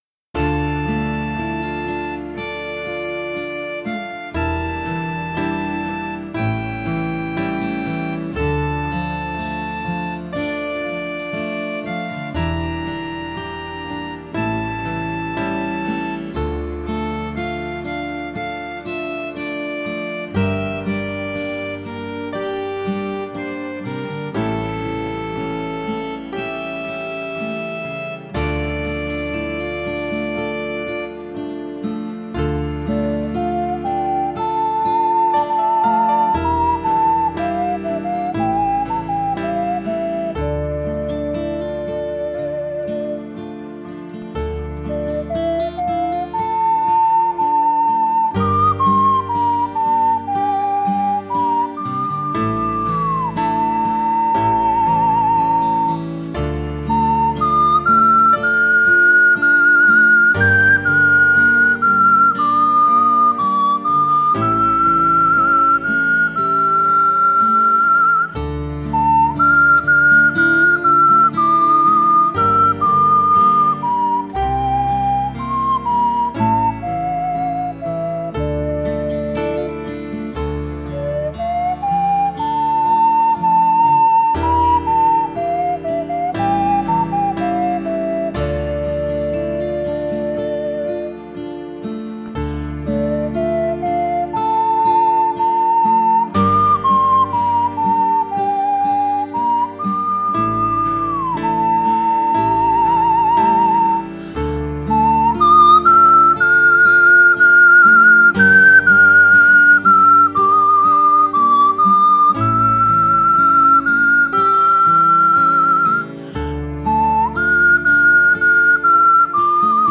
鼻笛練習用音楽素材
鼻笛教室等で使用の練習用素材はこちらにまとめます。
桜貝の歌　サンプル演奏付